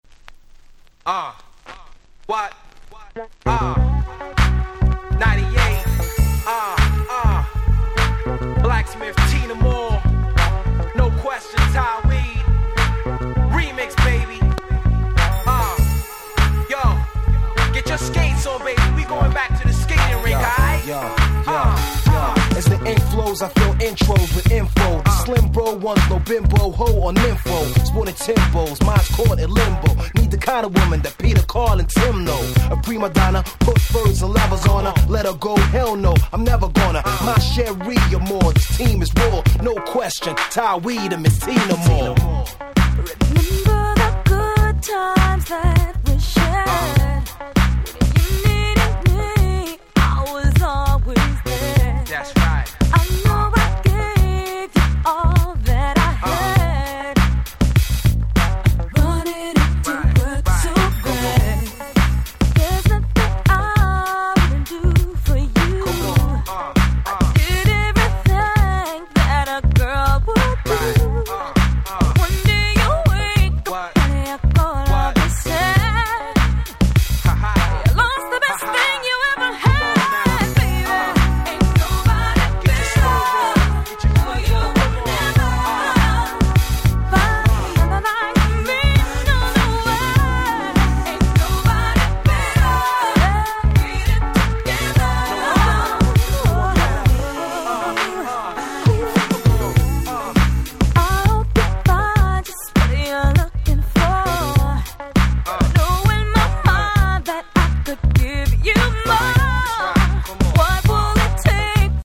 97' Nice R&B !!